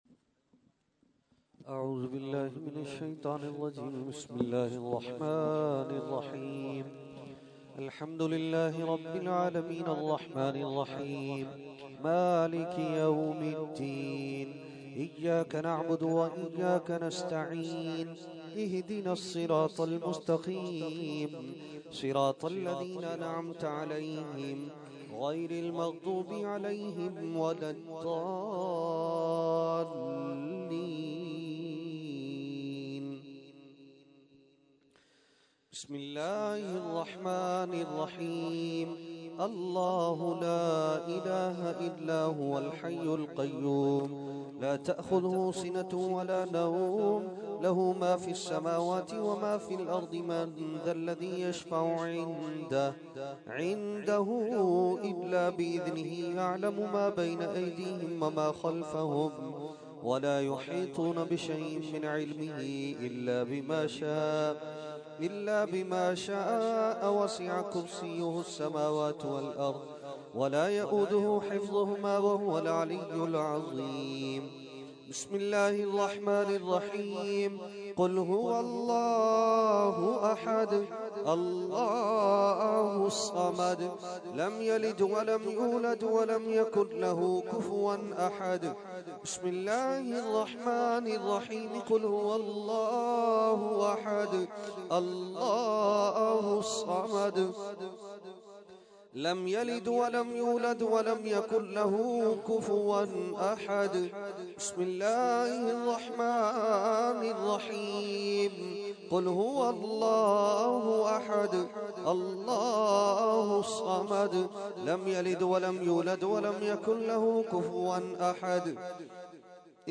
Category : Fatiha | Language : ArabicEvent : Mehfil 11veen Nazimabad 22 February 2014